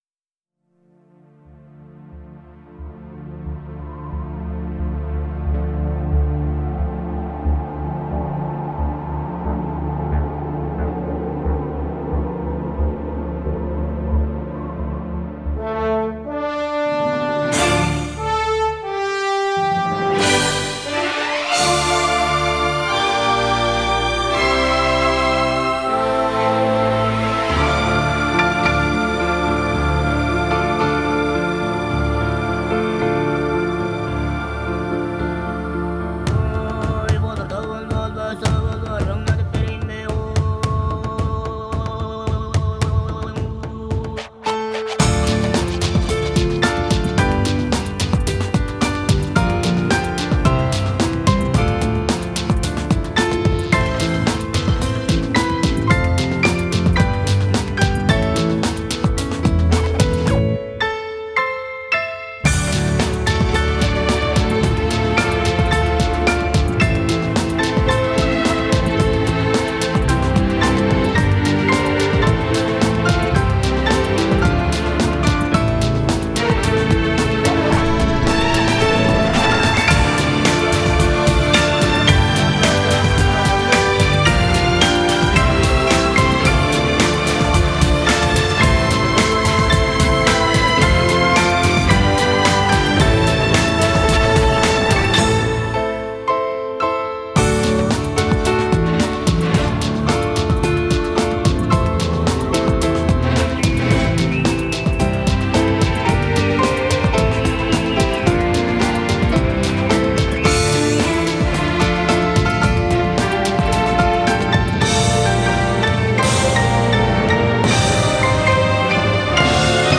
Genere: Instrumental.